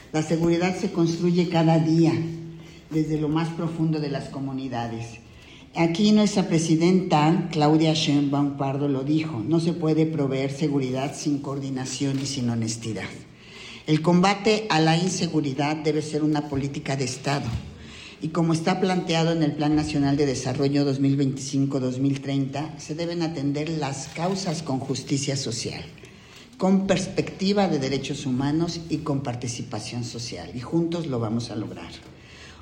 AudioBoletines
María del Rocío García Pérez, titular del SNDIF